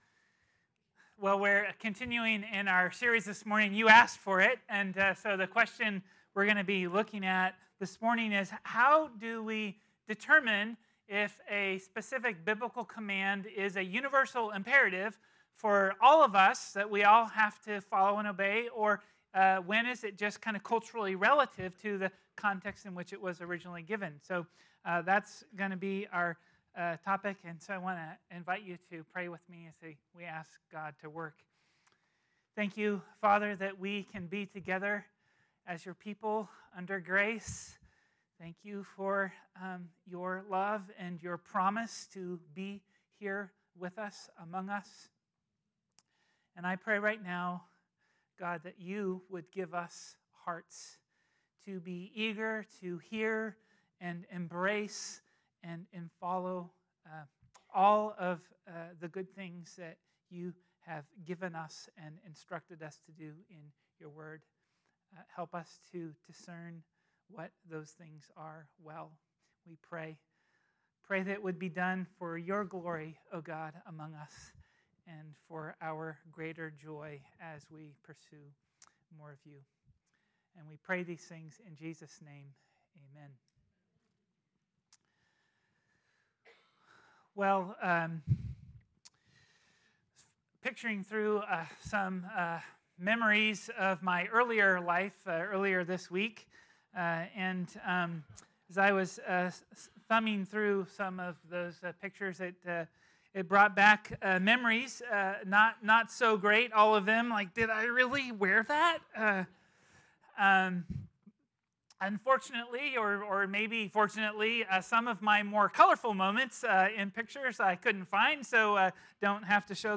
Type: Sermons Book